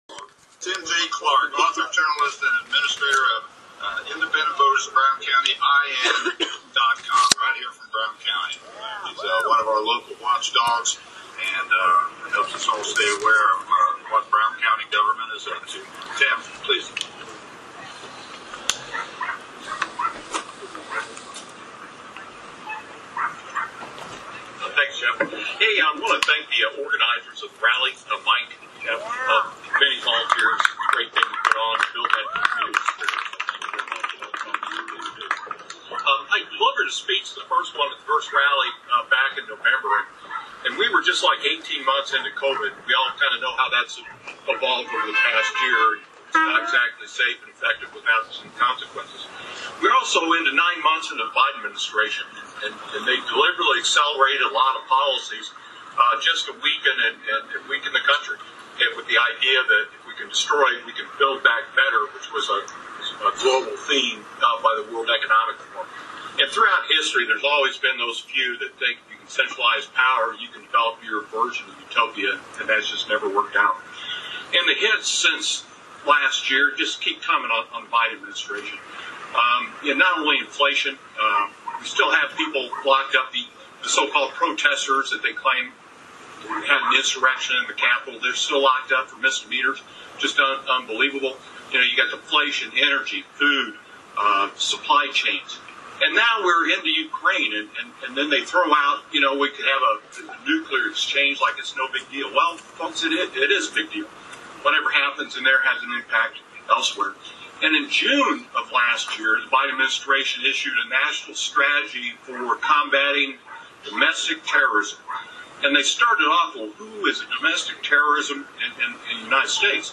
Freedom Fest – Video / Speeches